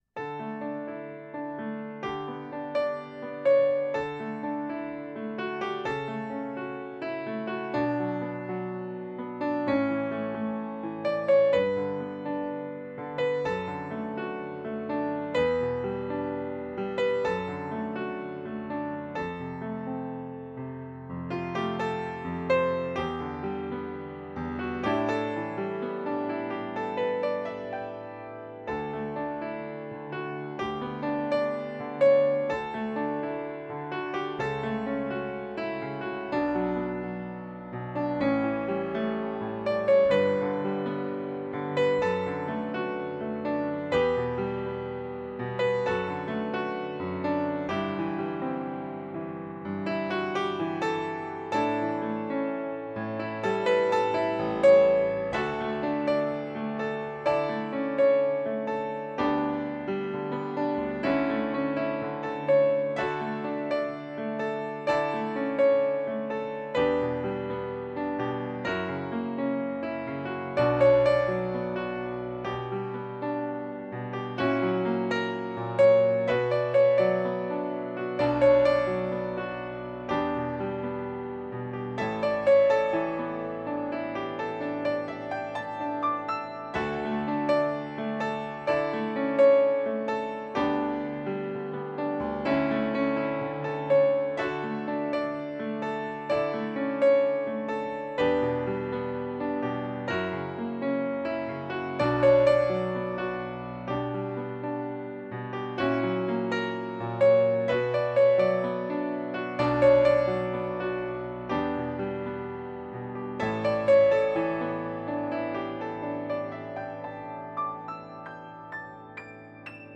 piano - romantique - melancolique - nostalgique - melodique